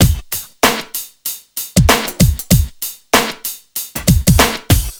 • 96 Bpm Drum Loop Sample D# Key.wav
Free breakbeat - kick tuned to the D# note. Loudest frequency: 3044Hz
96-bpm-drum-loop-sample-d-sharp-key-BzS.wav